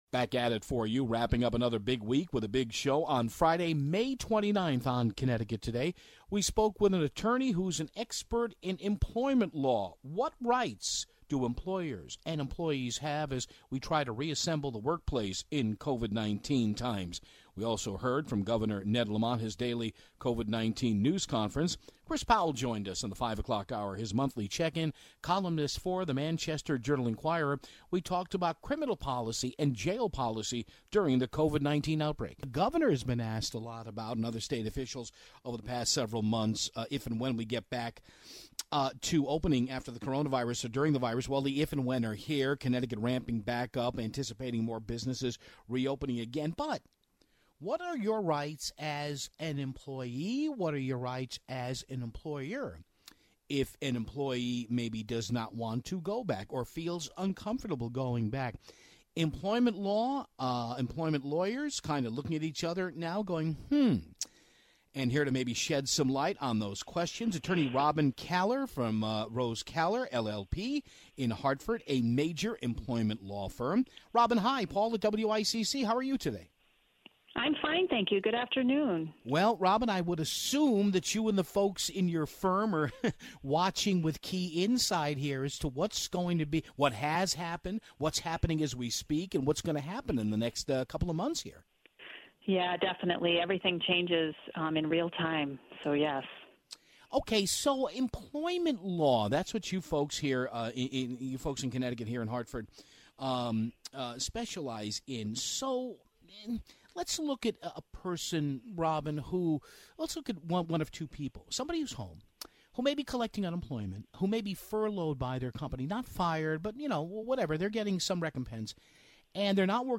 We carried portions of Governor Ned Lamont's daily press briefing